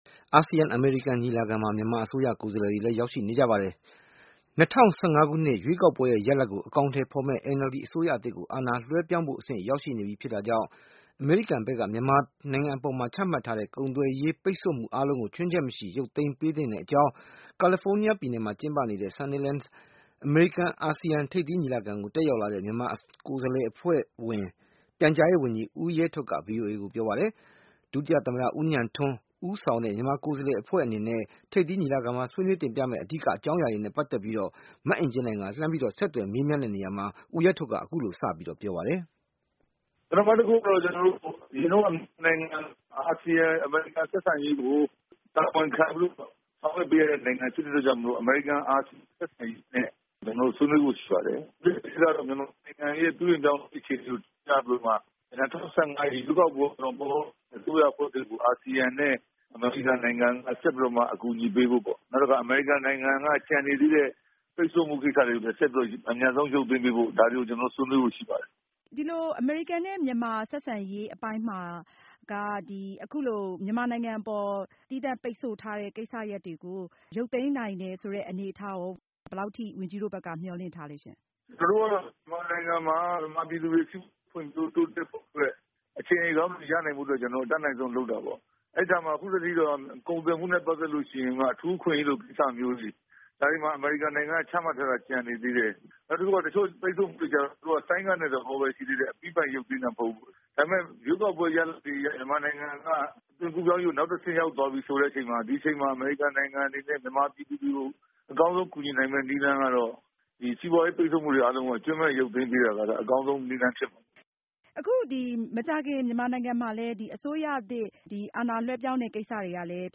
ဦးရဲထွဋ်နဲ့ အင်တာဗျူးး